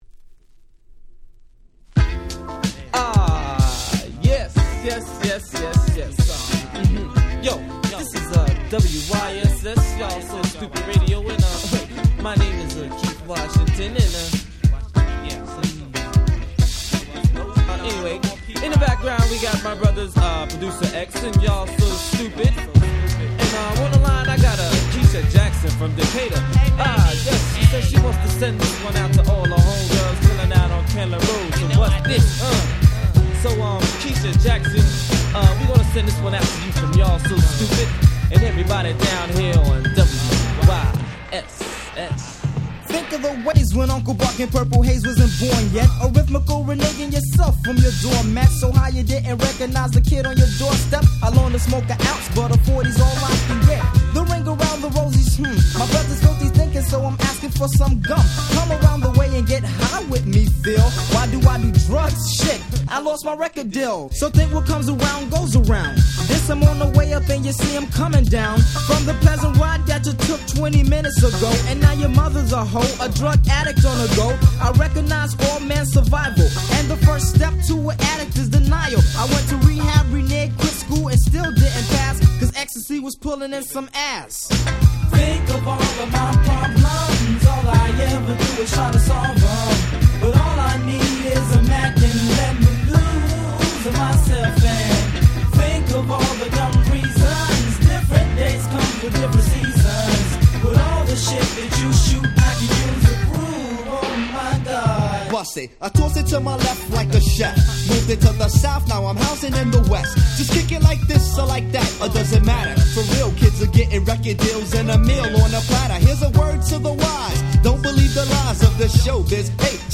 93' Very Nice Boom Bap / Hip Hop !!
Impeach Beatに軽快な上モノのキャッチーな1曲！！
ブーンバップ 90's